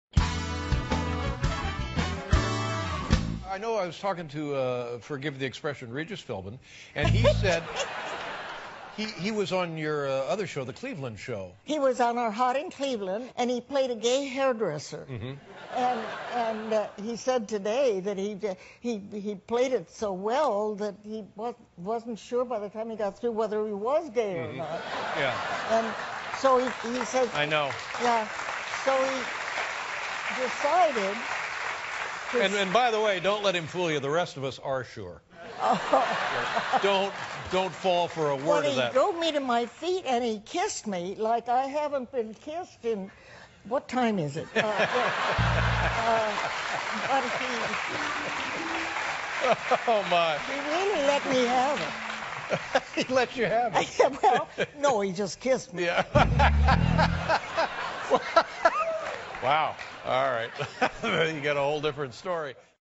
访谈录 2012-05-18&05-20 贝蒂.怀特谈里吉斯菲尔宾 听力文件下载—在线英语听力室